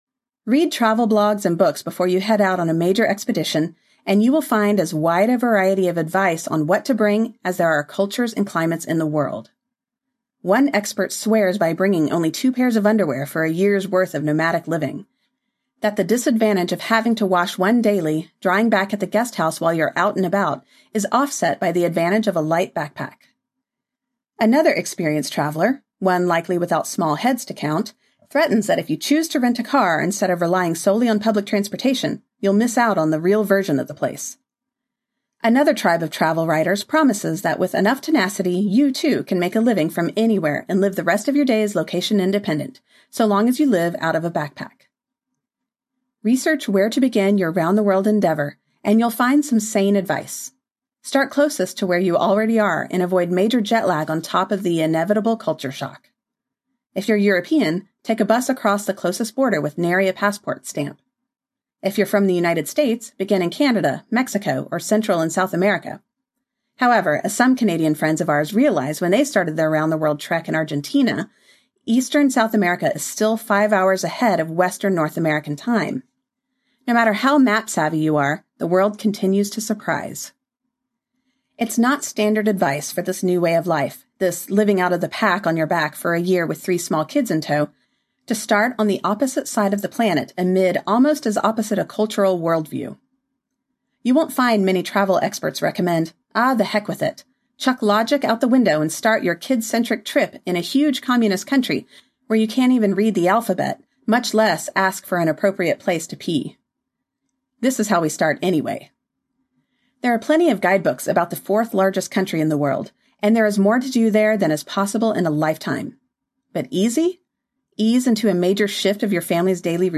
At Home in the World Audiobook
7.3 Hrs. – Unabridged